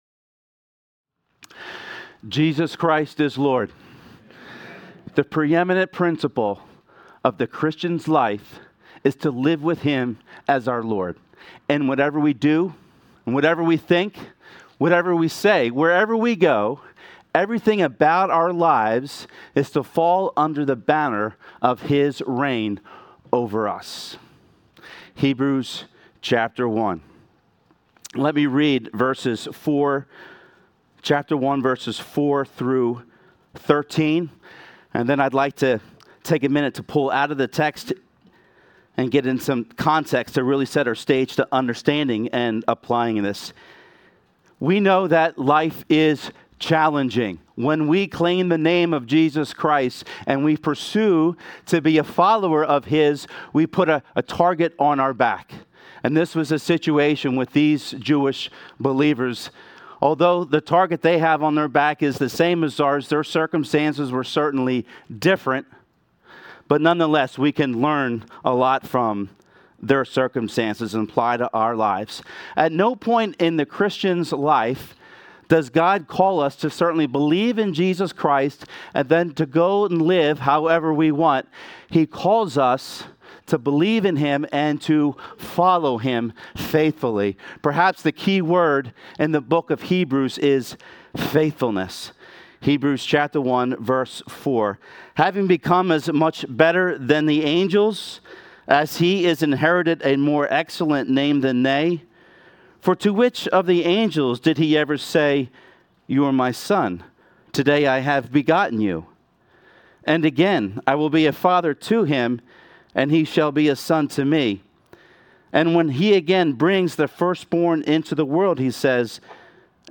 Sermons | Calvary Chapel